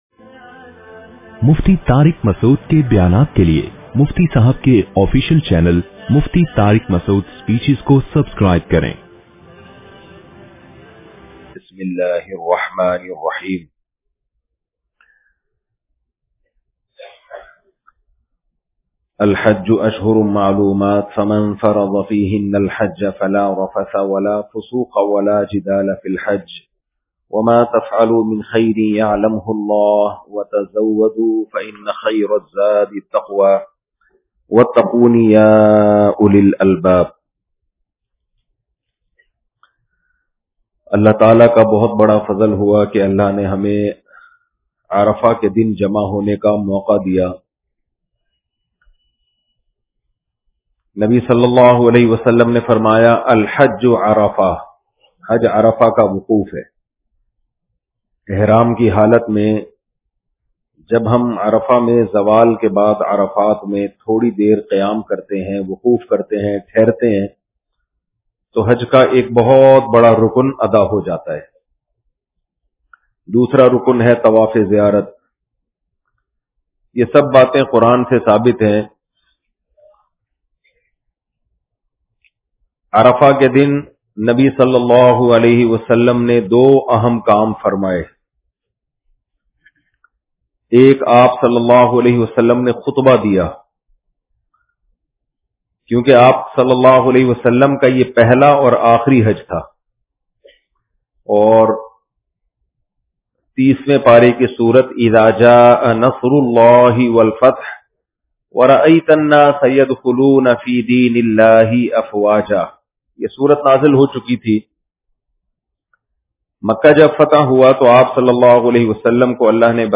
Arfaat Bayan- Bayan by Mufti Tariq Masood Sahab
Audio Bayan